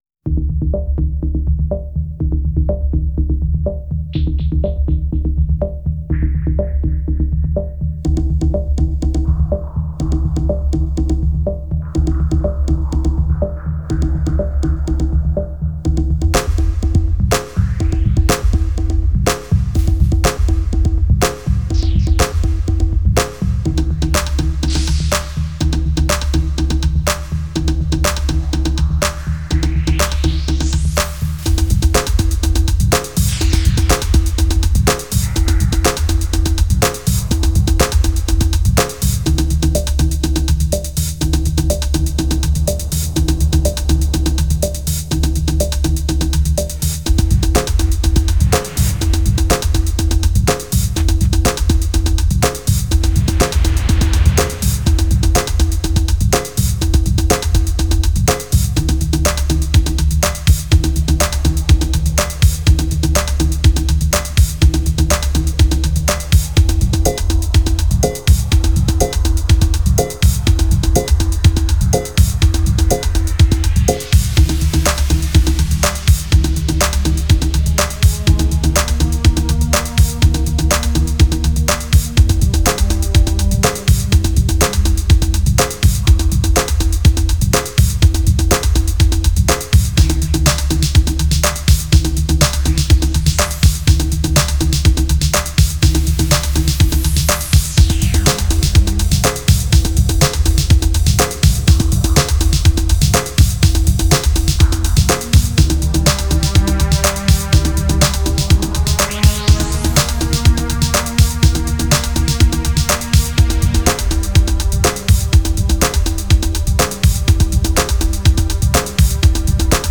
Genre: Electronic, Acid House, Deep House